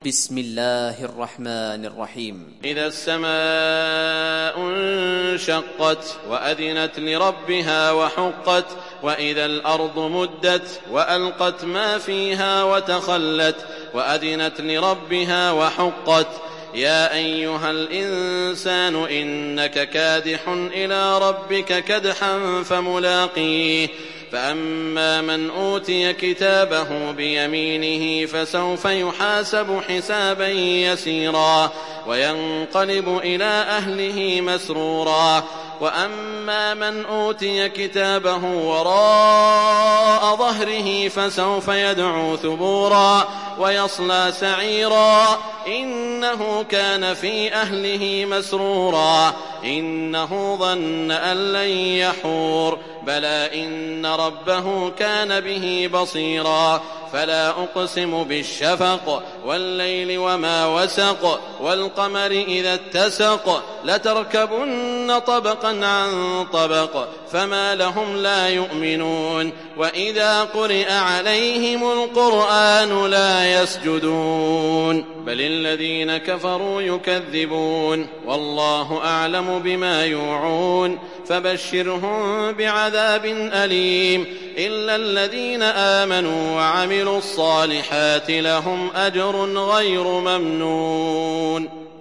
Inşikak Suresi mp3 İndir Saud Al Shuraim (Riwayat Hafs)